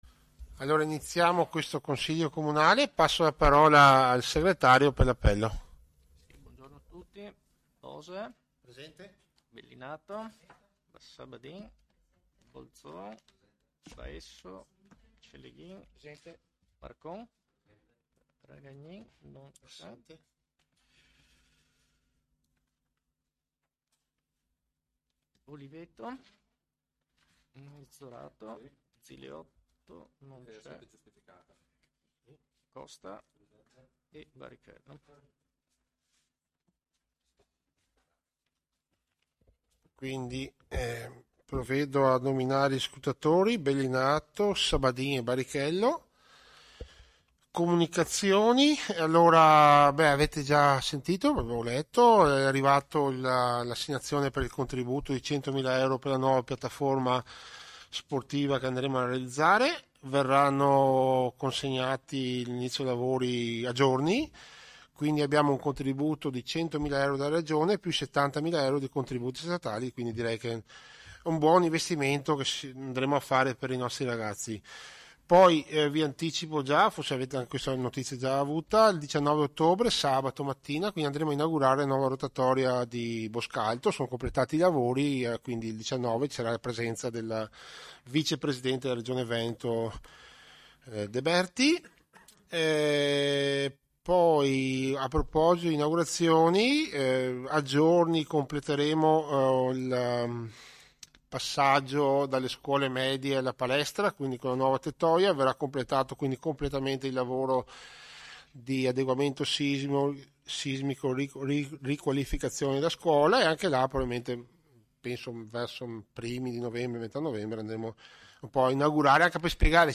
Seduta di Consiglio Comunale del 28/09/2024